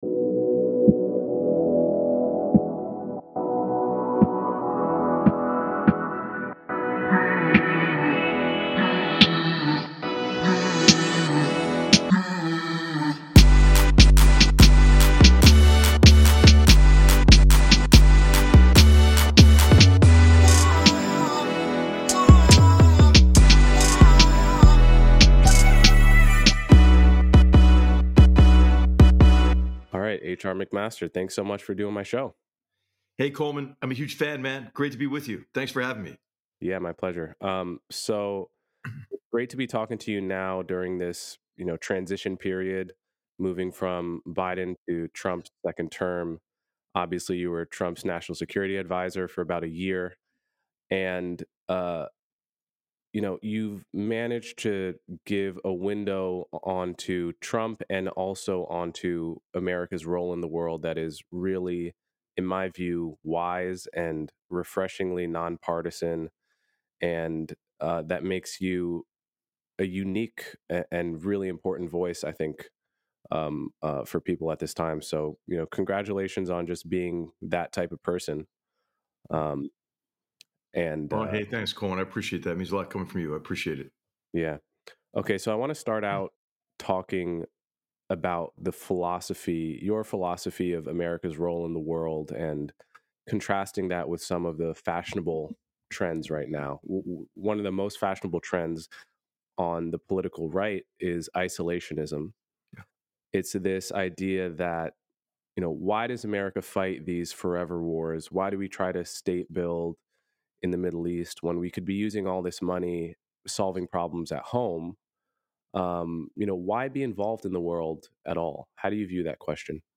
In this conversation, Coleman Hughes interviews H.R. McMaster, discussing America's role in the world, the military-industrial complex, the concept of a deep state, bureaucratic resistance, lessons from the Vietnam War, and the strengths and weaknesses of Donald Trump as a leader.